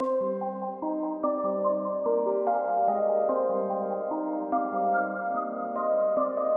描述：和声小调音阶 d nexus钢琴，85为半音程
Tag: 146 bpm Trap Loops Piano Loops 1.11 MB wav Key : D